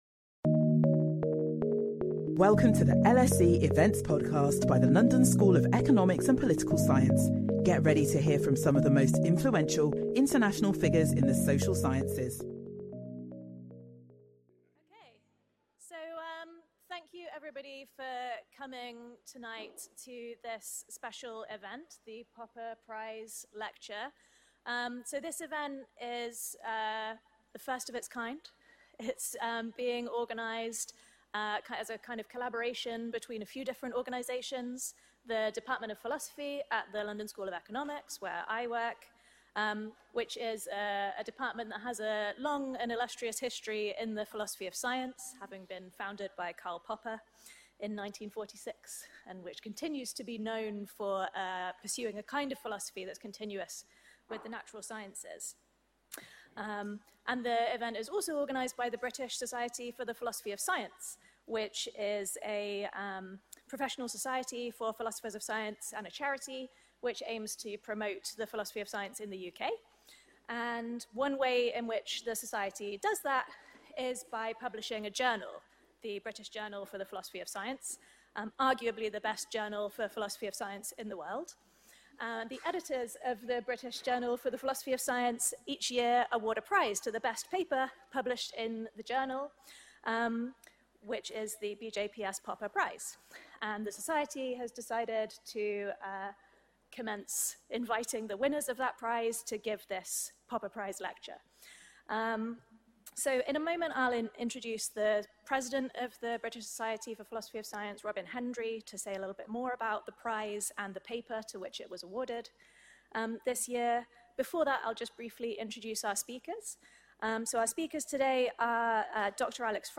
This inaugural lecture will look at key issues in the study of women’s health through the lens of reproductive histories, looking at both contingent and cumulated events to include physical and mental shocks such as conflict and disasters which would eventually have an impact later in life.